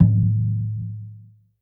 Perc.wav